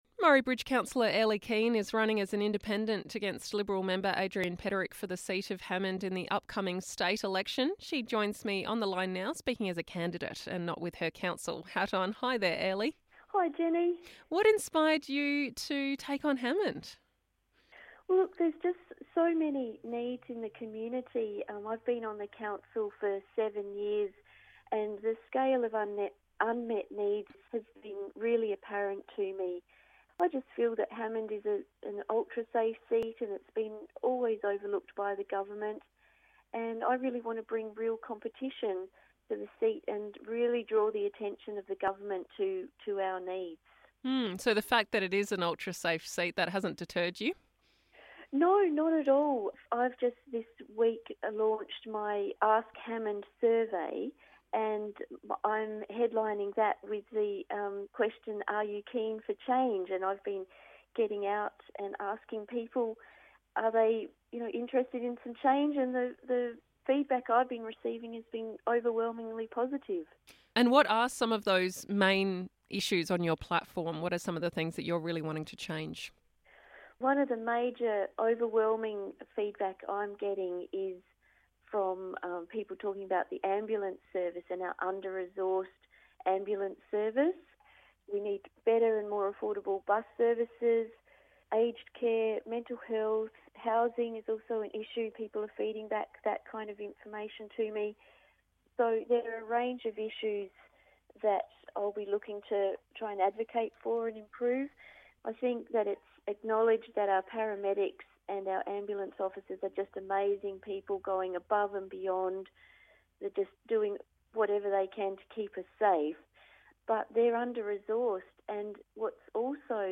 Keen for Change - A Chat with the Independent Candidate for Hammond